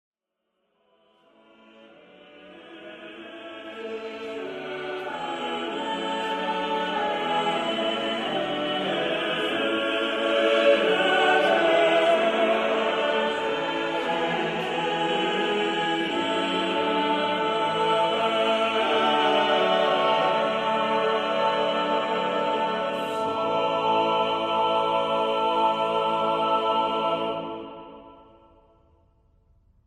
Uitgevoerd door Oxford Camerata.